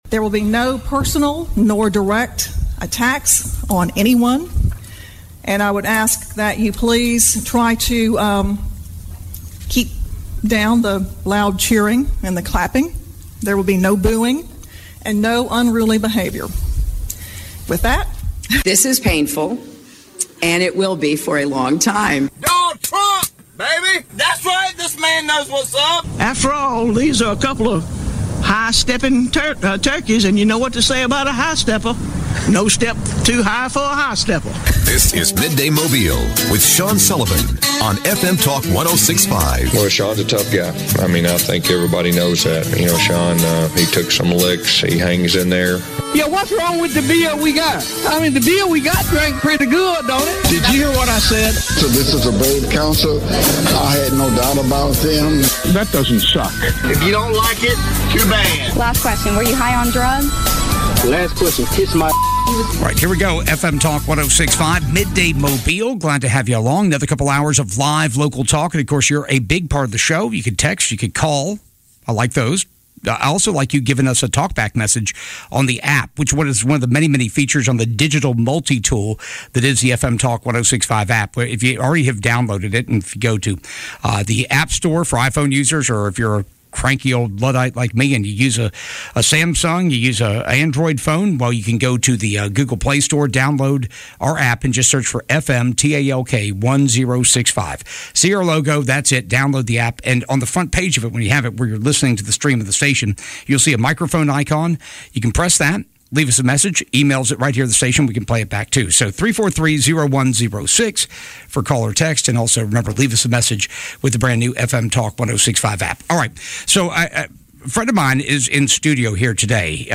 Midday Mobile - Danny Lipford is in studio to answer all your home improvement questions - January 12, 2022